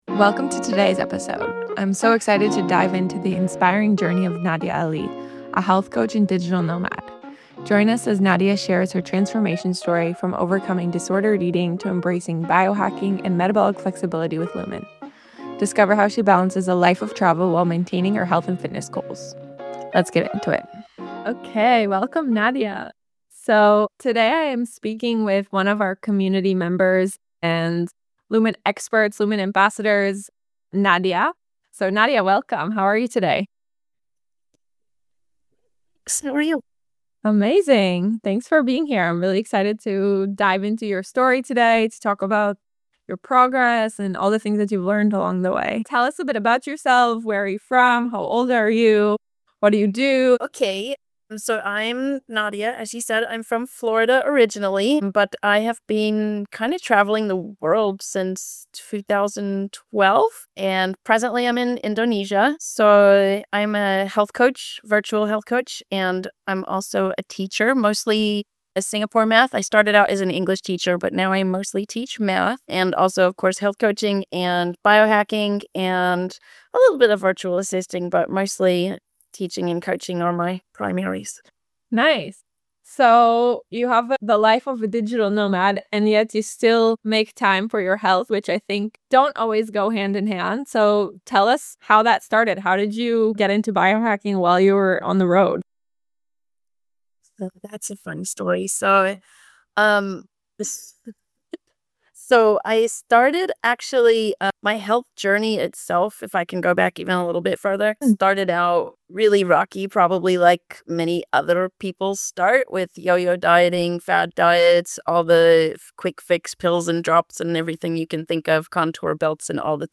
Tune in for an inspiring conversation filled with practical tips and personal stories that highlight the power of resilience and self-discovery.